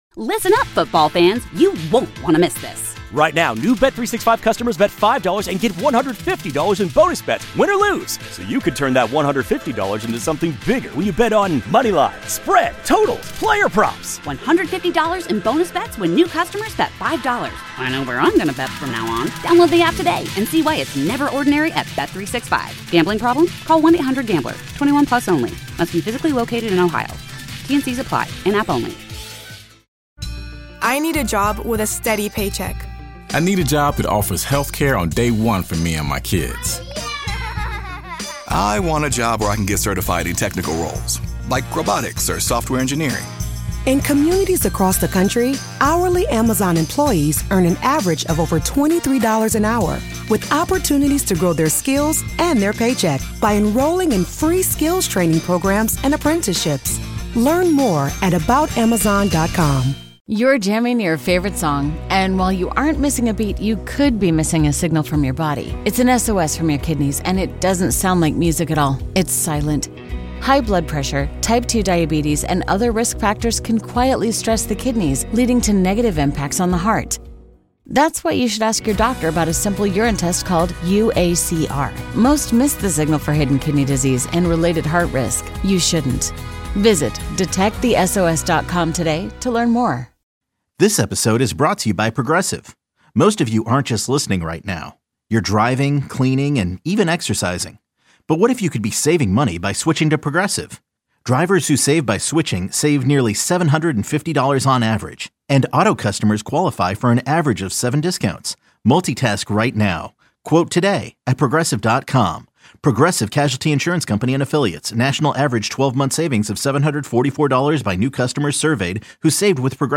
Indulge your inner curiosity with caller driven conversation that makes you feel like you’re part of the conversation—or even better, eavesdropping on someone else’s drama.
Listen to The Slacker Show weekdays from 3-7p on Alice 105.9 | KALC-FM.